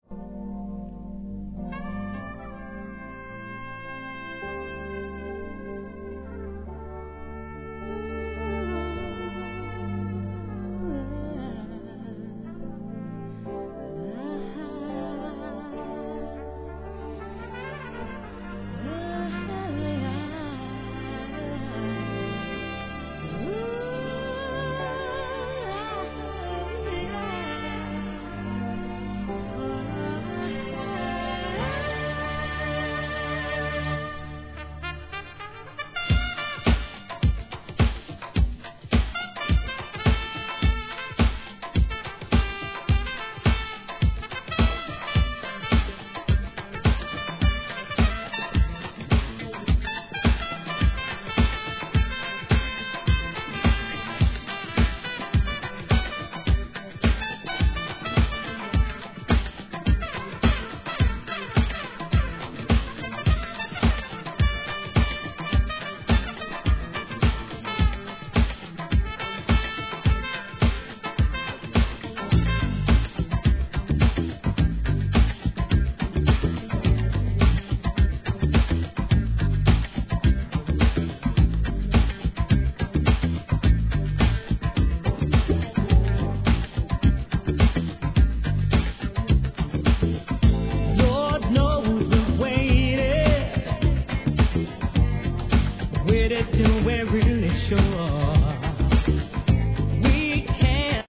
HIP HOP/R&B
女の子だけを第一義に作られたスーパー・ラブリー・テンダー6曲いり!!